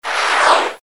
闪白音效-唰
【简介】： 唰声、转场音效